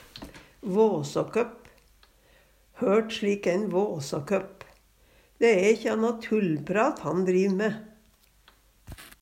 våsåkøpp - Numedalsmål (en-US)